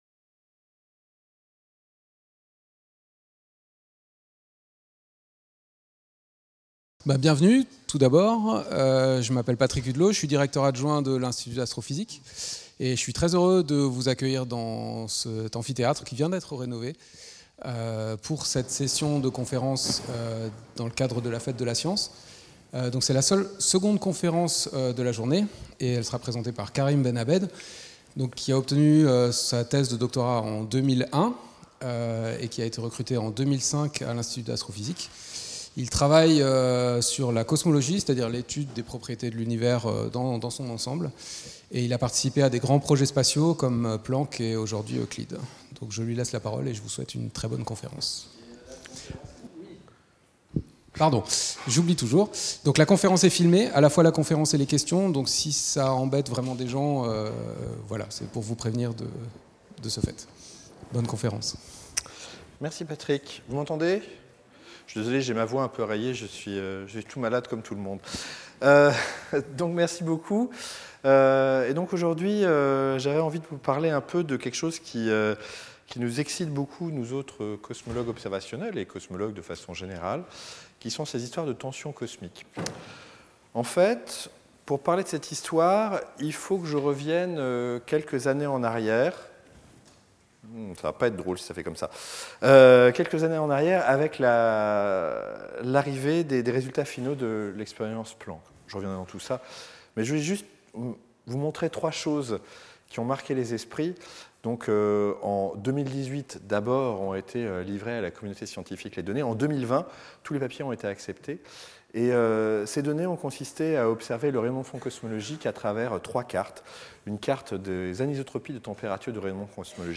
Cette conférence